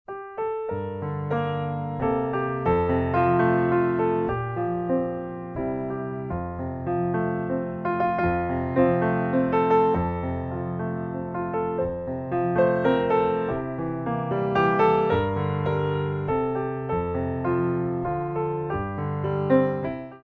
By Pianist & Ballet Accompanist
Piano selections include:
Plié facing barre